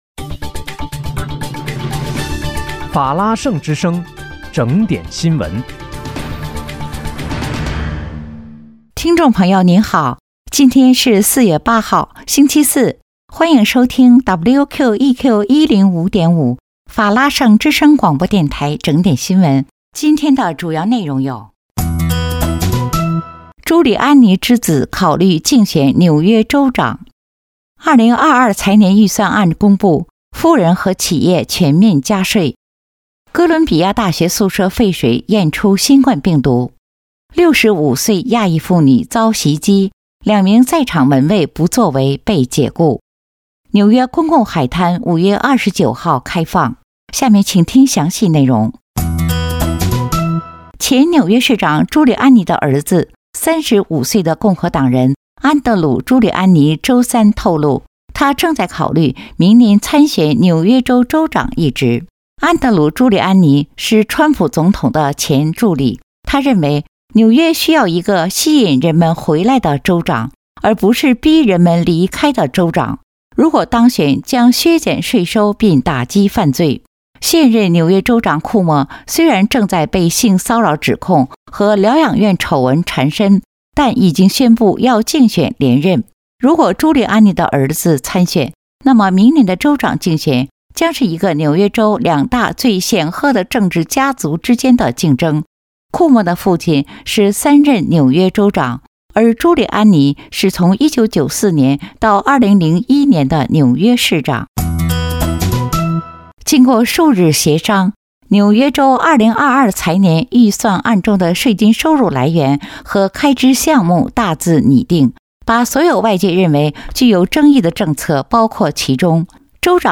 4月8号（星期四）纽约整点新闻